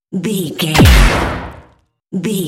Dramatic hit electricity
Sound Effects
Atonal
heavy
intense
dark
aggressive